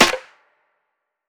HFMSnare5.wav